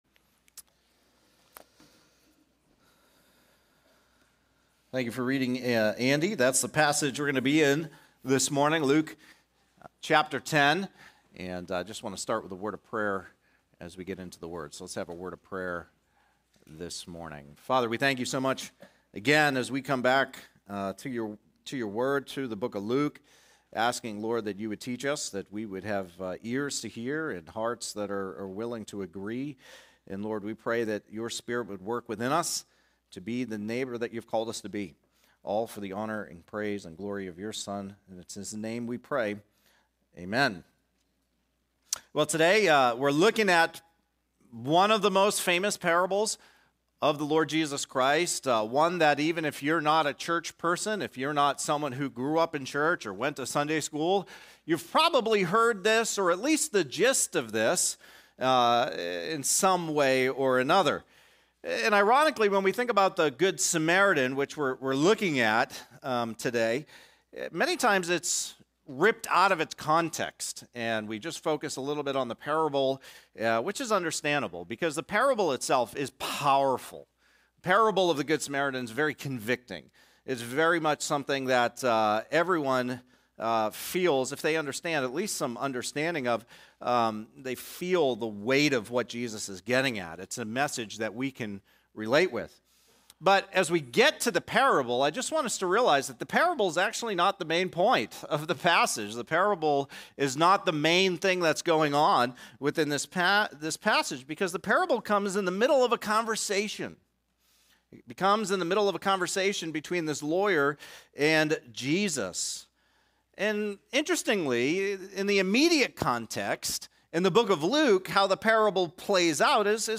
Sermons | Gospel Life Church